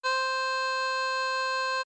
harmonica-Do4.wav